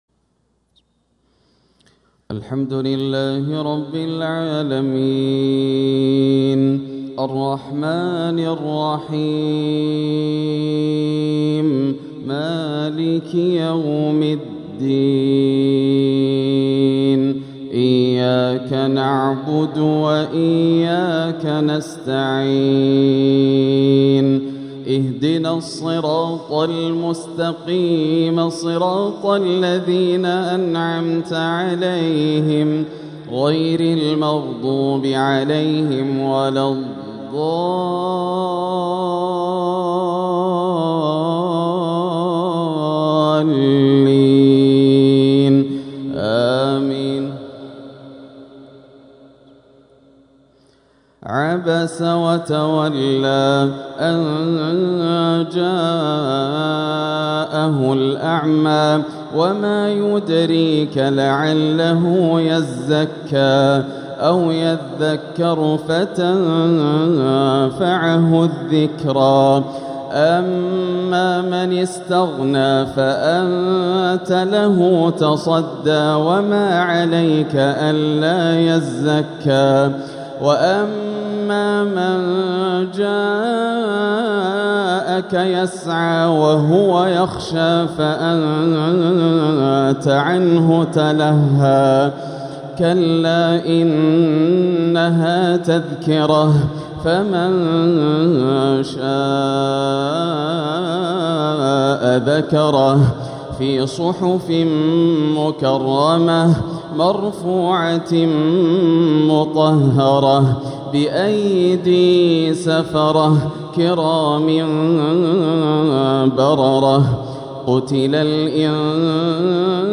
تلاوة لسورة عبس كاملة | مغرب الأربعاء 23 ربيع الآخر 1447هـ > عام 1447 > الفروض - تلاوات ياسر الدوسري